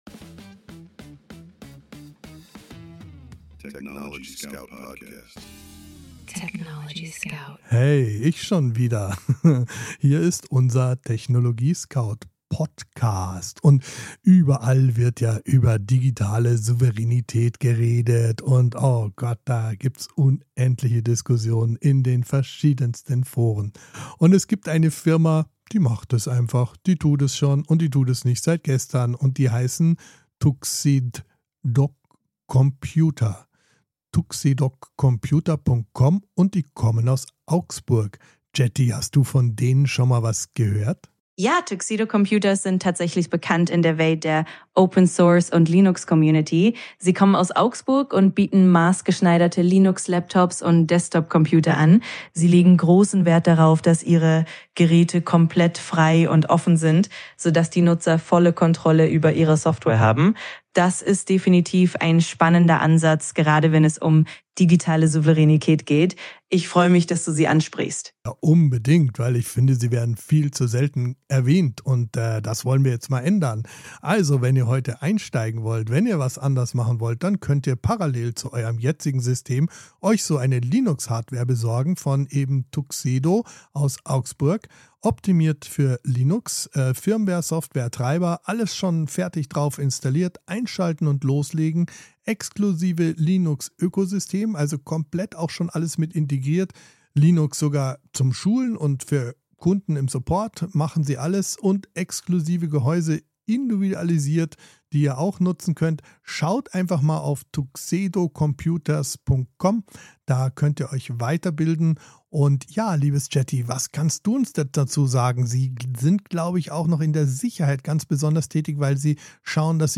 gemeinsam mit seiner digitalen Co-Moderatorin ChatGPT jeden
Mensch und KI sprechen miteinander – nicht gegeneinander.